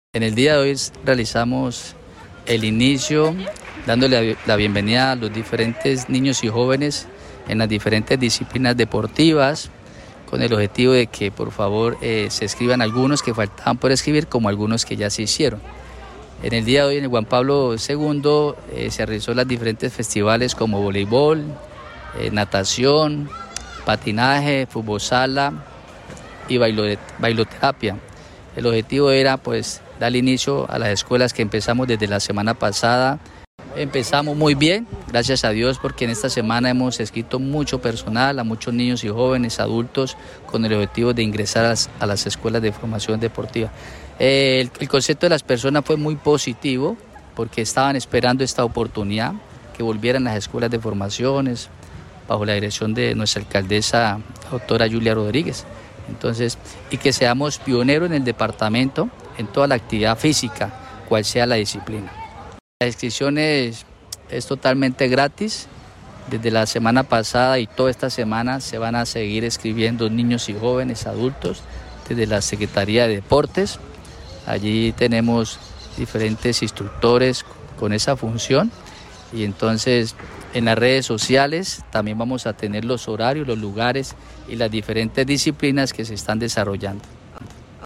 Uver Almanza, Secretario de Deportes.mp3